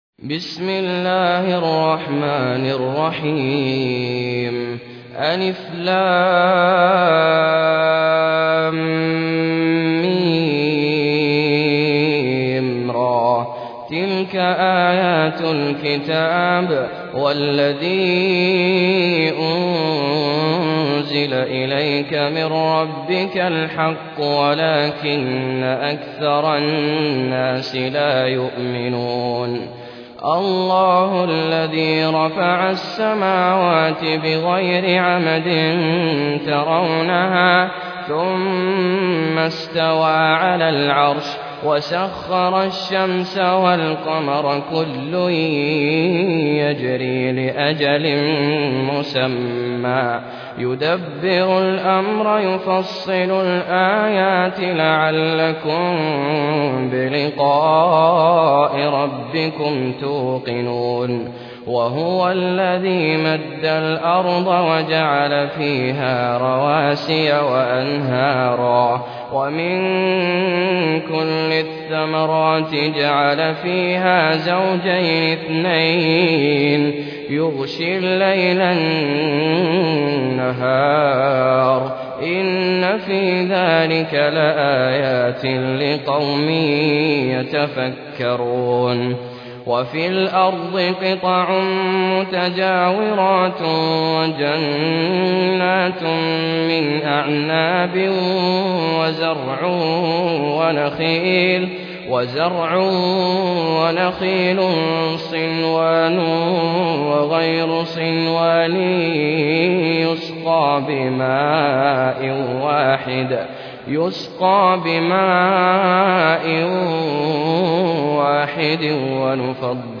High Quality Quranic recitations and Islamic Lectures from selected scholars